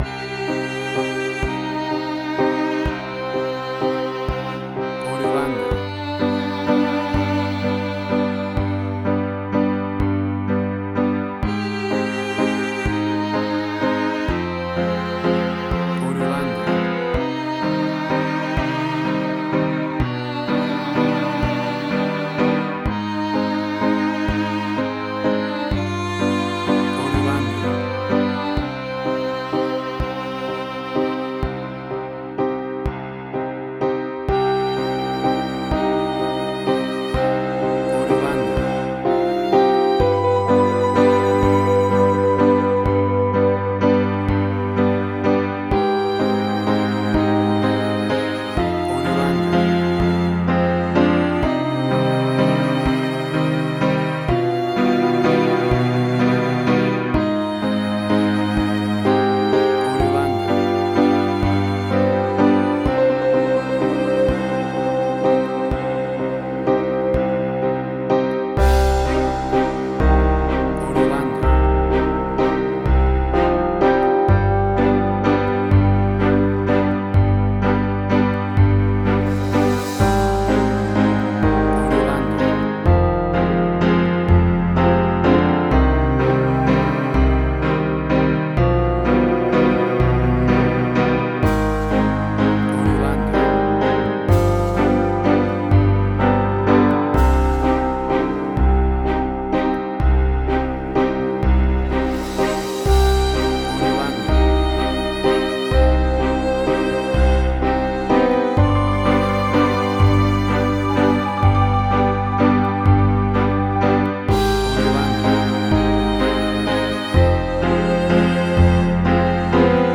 Spaghetti Western, similar Ennio Morricone y Marco Beltrami.
Tempo (BPM): 42